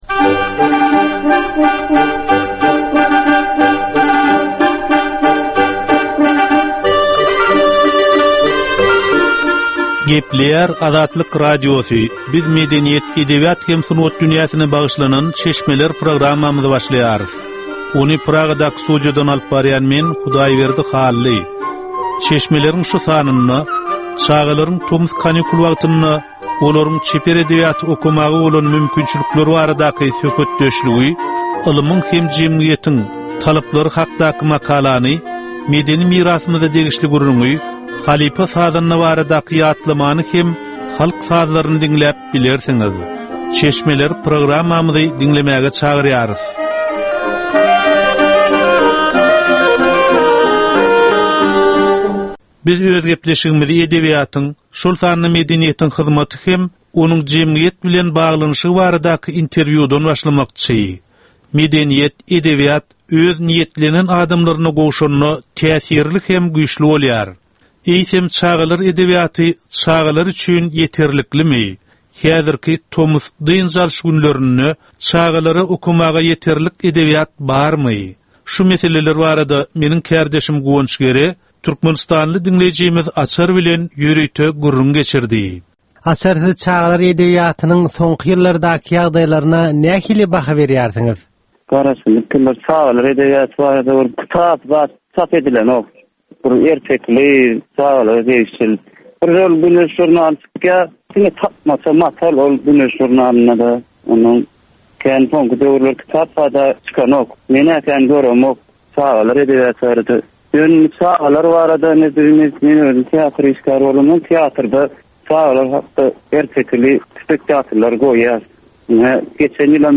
Edebi, medeni we taryhy temalardan 25 minutlyk ýörite geplesik.